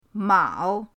mao3.mp3